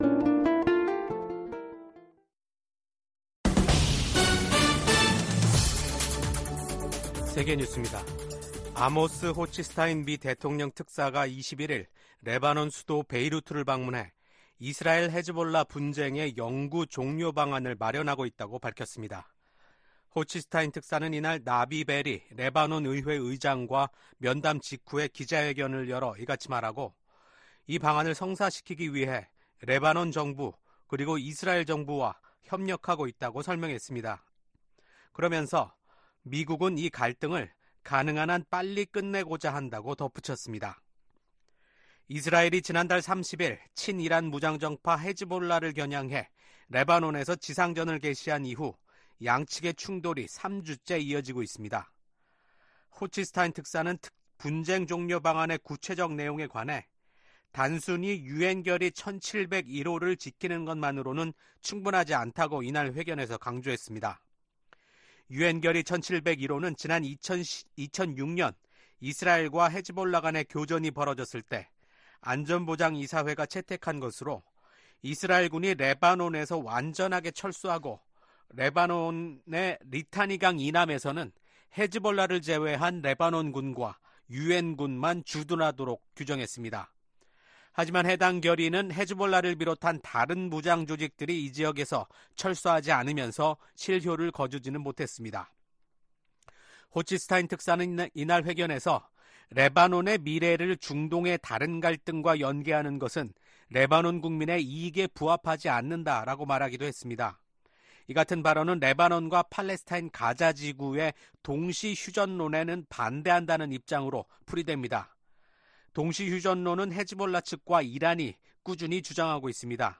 VOA 한국어 아침 뉴스 프로그램 '워싱턴 뉴스 광장' 2024년 10월 22일 방송입니다. 북한이 대규모 병력을 우크라이나 전장에 투입하기로 했다는 한국 정부의 발표에 대해 미국 정부가 중대한 우려의 입장을 밝혔습니다. 북한에 인력을 요청할 수밖에 없다면 이는 러시아의 절망의 신호일 것이라고 지적했습니다.